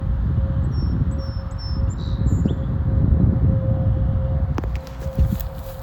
song sparrow.mp3􀄩
went to this grassland and saw and heard a buncha cool sparrows, many of which were entirely new to me:
song sparrow (this one let me get real close while he was singing)